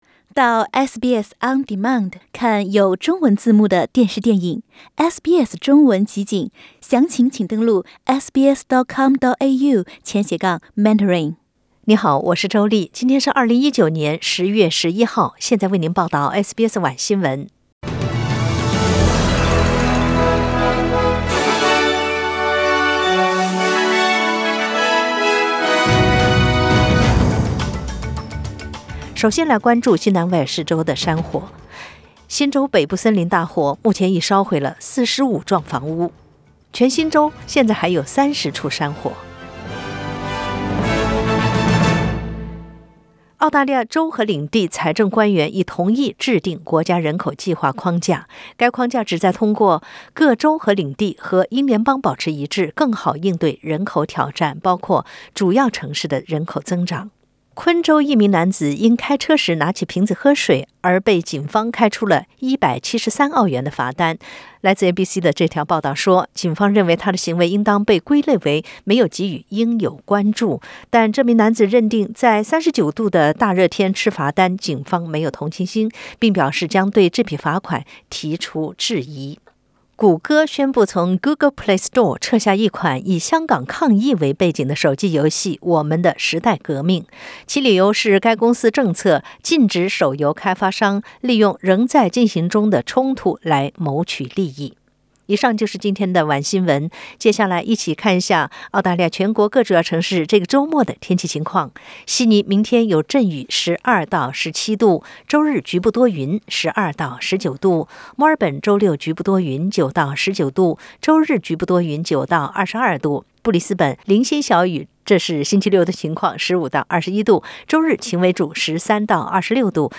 SBS 晚新闻 （10月11日）